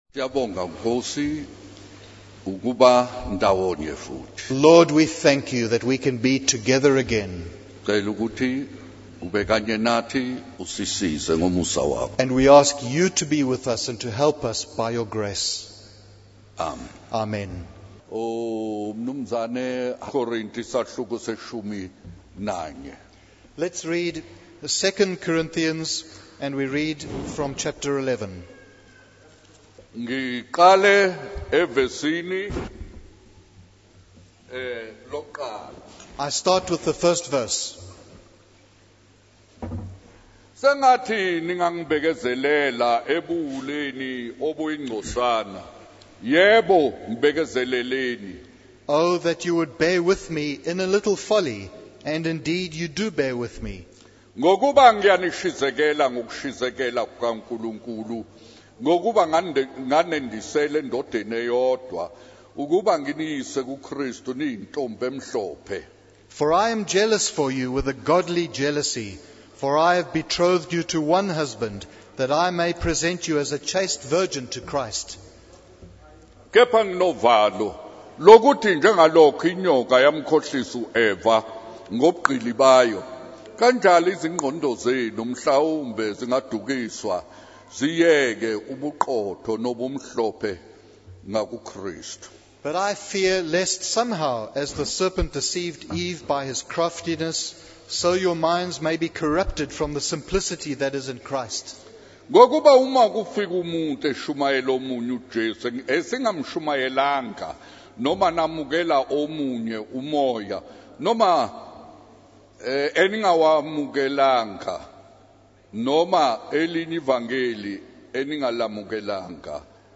In this sermon, the speaker emphasizes the hardships and sufferings that the apostle Paul endured in his ministry.